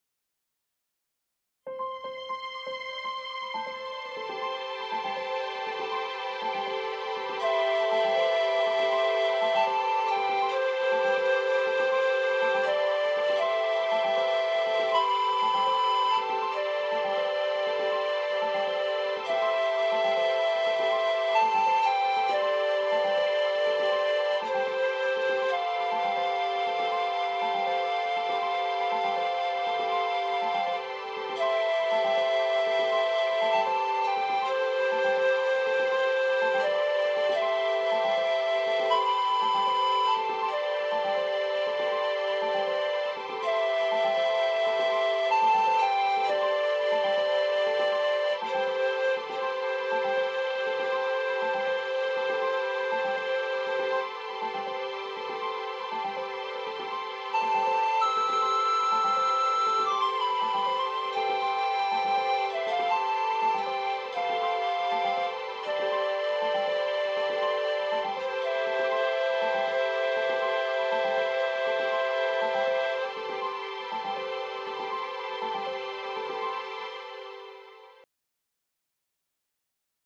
Nature Documentary, Theater Music